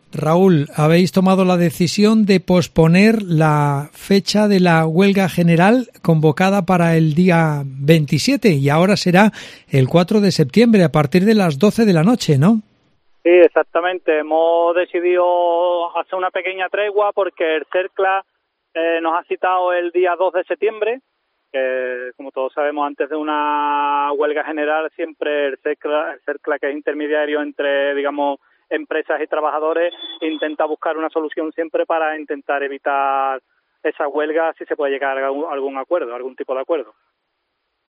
Para el informativo Mediodía Cope Provincia de Cádiz